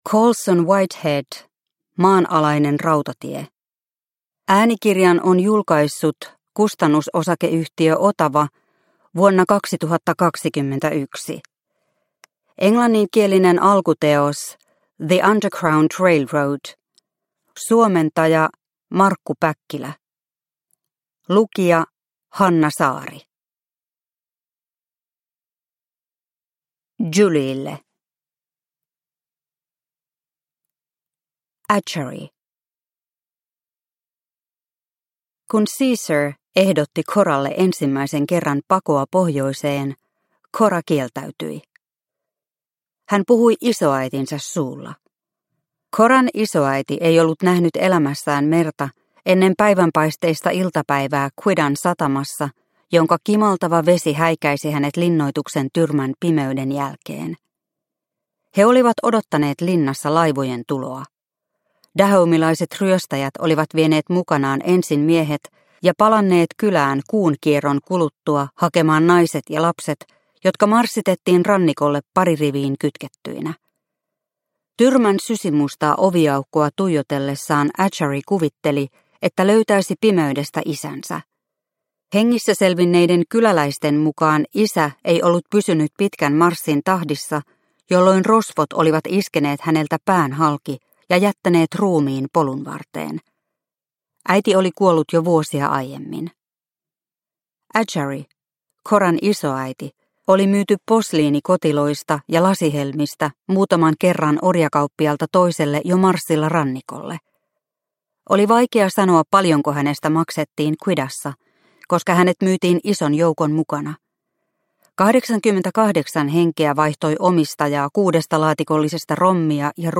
Maanalainen rautatie – Ljudbok – Laddas ner